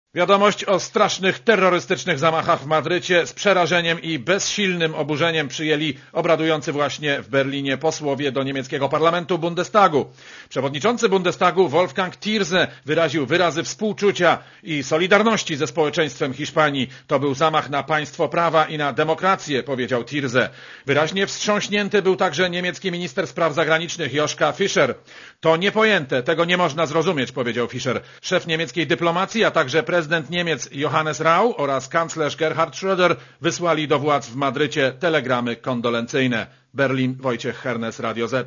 Korespondencja z Berlina